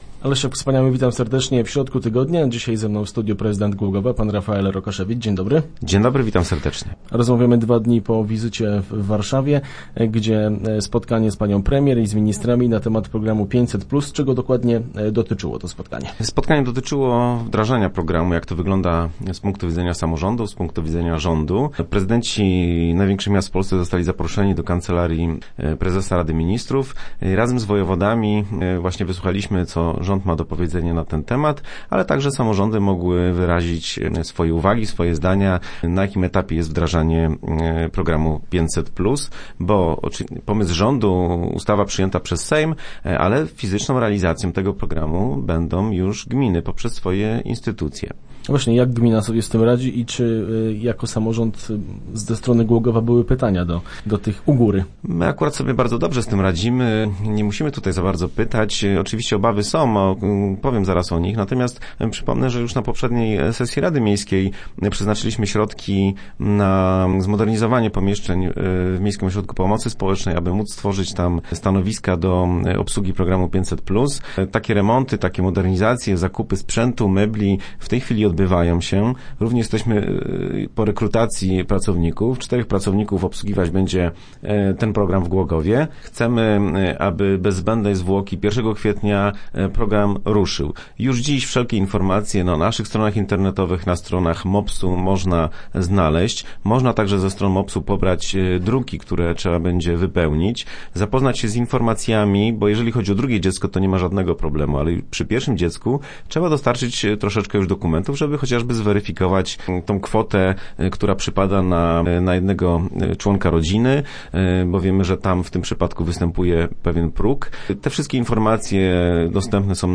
0930_rokasz_re.jpgPrezydent Głogowa Rafael Rokaszewicz gościł w Kancelarii Premiera, gdzie odbyło się spotkanie z władzami miast na temat zbliżającego się terminu wprowadzenia programu „Rodzina 500 plus”. O szczegółach spotkania R. Rokaszewicz opowiadał w środę w radiowym studiu.